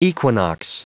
Prononciation du mot equinox en anglais (fichier audio)
Prononciation du mot : equinox